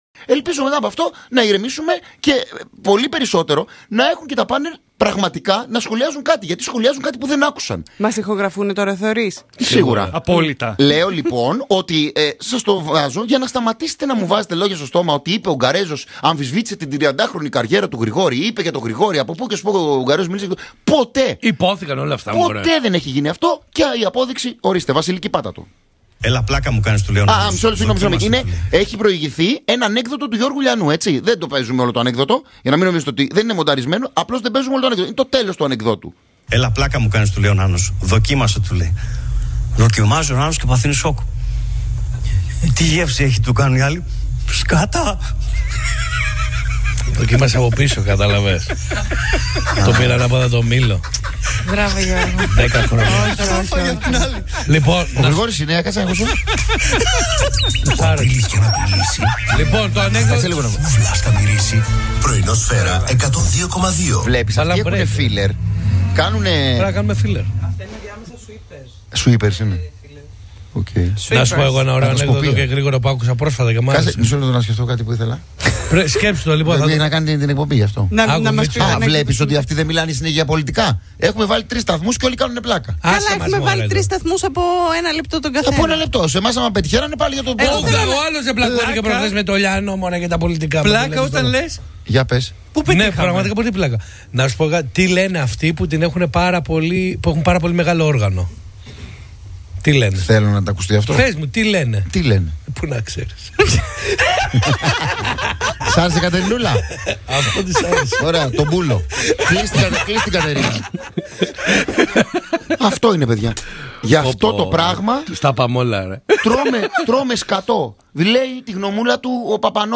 Το ηχητικό απόσπασμα από τη ραδιοφωνική εκπομπή του Γρηγόρη Αρναούτογλου, που έπαιξε μέσα στην εβδομάδα, και στο οποίο ο παρουσιαστής λοιδορούσε τον ίδιο